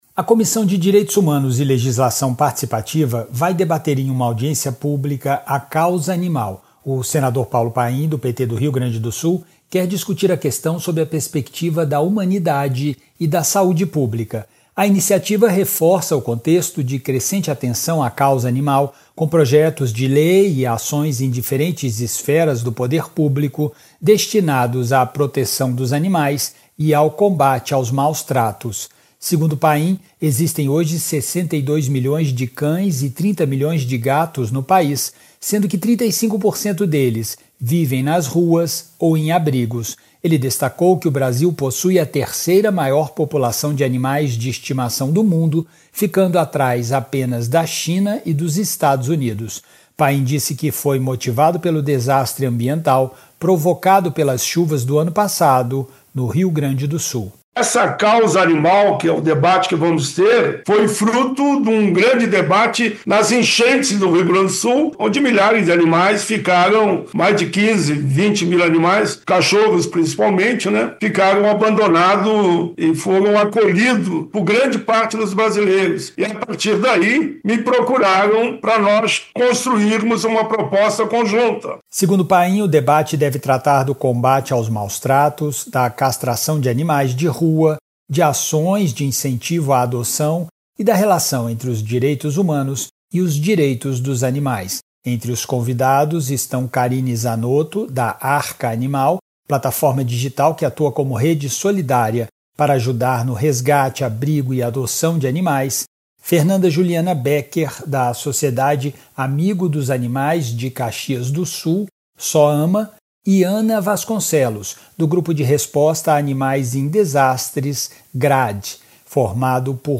Senador Paulo Paim